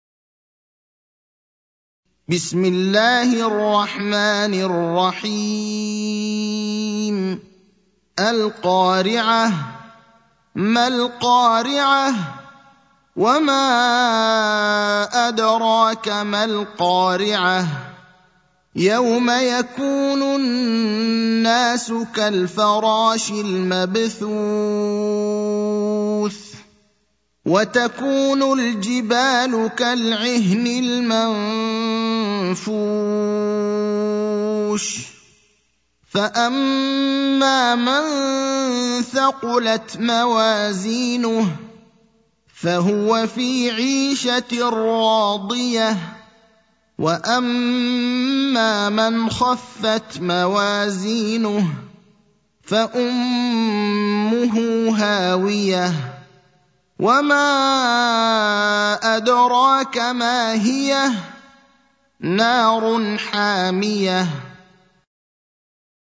المكان: المسجد النبوي الشيخ: فضيلة الشيخ إبراهيم الأخضر فضيلة الشيخ إبراهيم الأخضر القارعة (101)